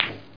1 channel
BALLHIT4.mp3